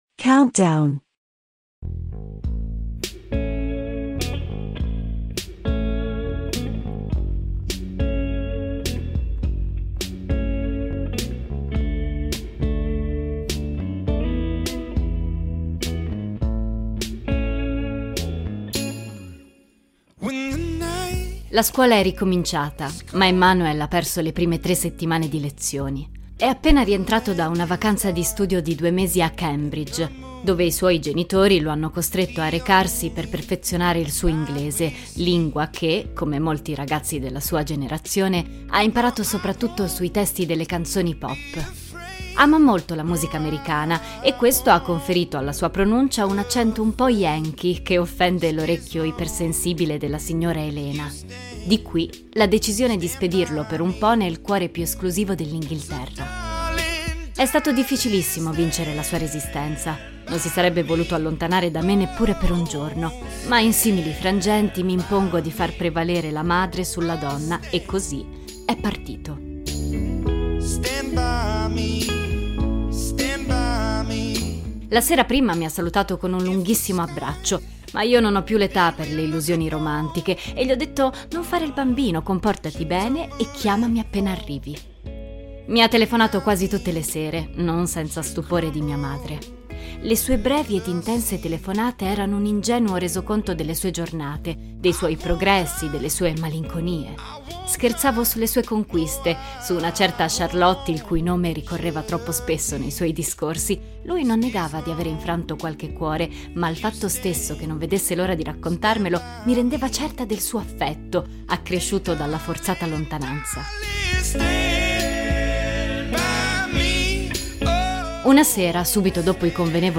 I brani che si ascoltano nell'episodio sono "Stand by me" di Ben E. King e un bizzarro rifacimento di "Buddy Holly" degli Weezer.
The songs that play in the episode are "Stand by me" by Ben E. King and a bizarre remake of "Buddy Holly" by Weezer.